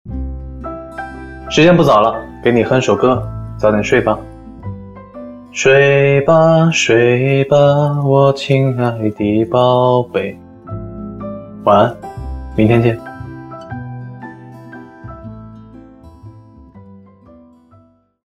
每晚他总会给我讲15分钟情话，这里只允许给你听一小段哦（偷偷点击下方音频）：
胡一天对我说的话.mp3